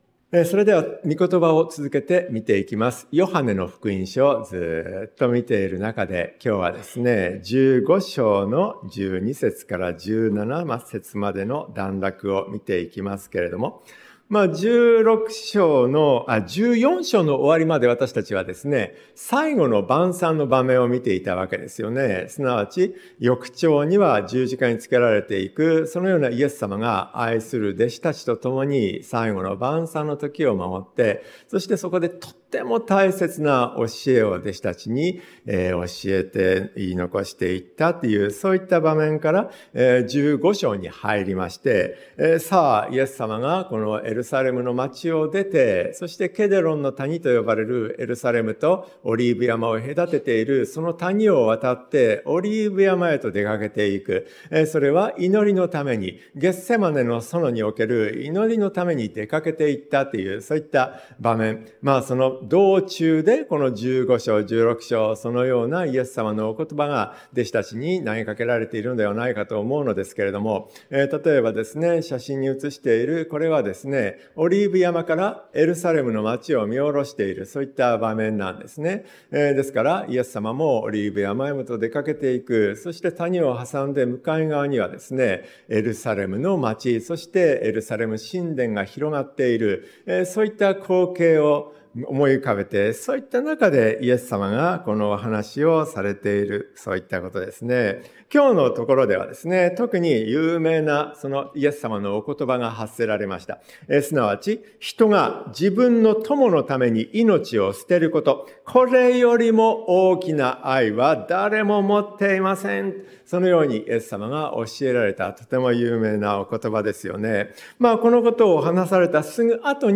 主イエスの友 説教者